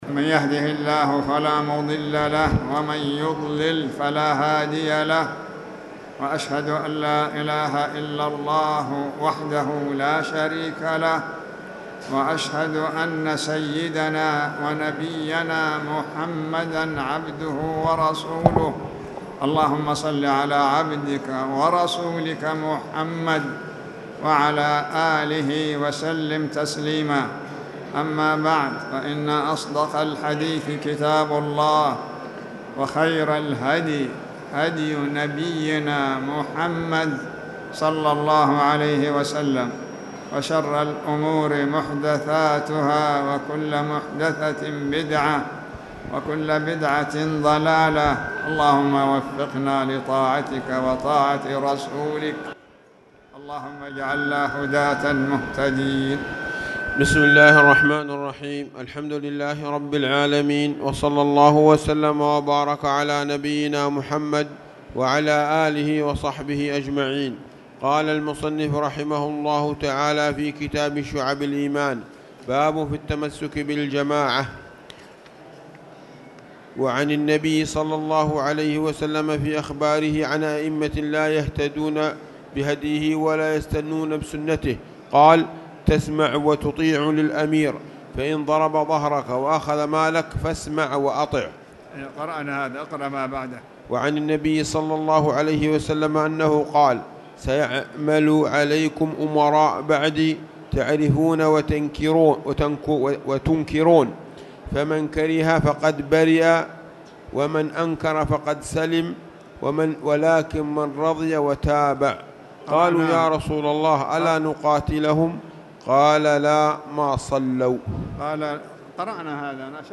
تاريخ النشر ١٤ ربيع الأول ١٤٣٨ هـ المكان: المسجد الحرام الشيخ